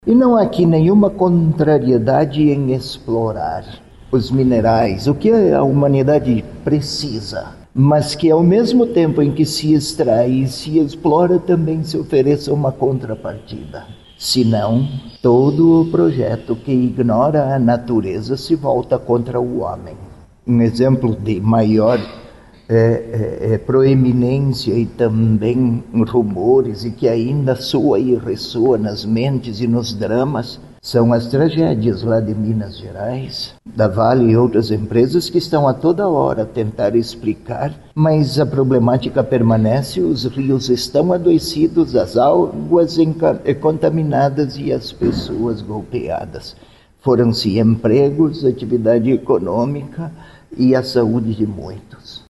A divulgação deste ano aconteceu durante coletiva de imprensa, na Cúria Metropolitana, na capital.